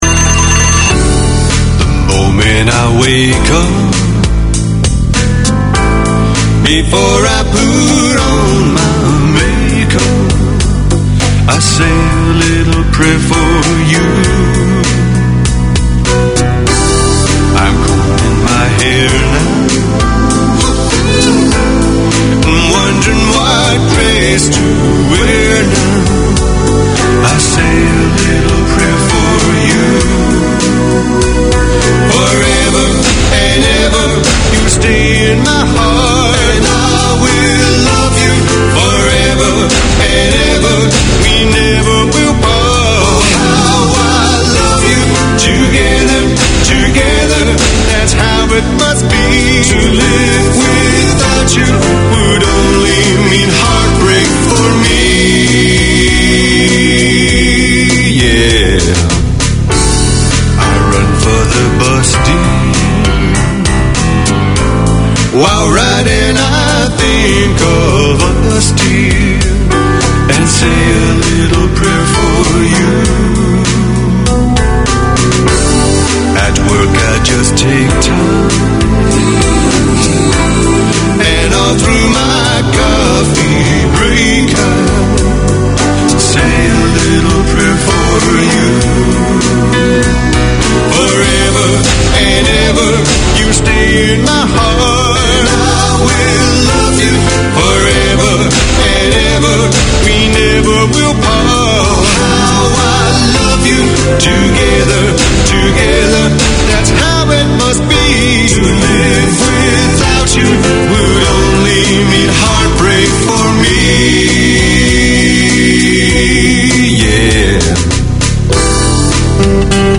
Unfiltered Conversations: Chai & Chat, is a safe and open space where young South Asian girls, can openly discuss, debate, and ask questions about relationships, culture, identity, and everything in between. They are on-air to break the stigma, share experiences, and support each other through honest conversations over a cup of chai.